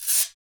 PRC METALG01.wav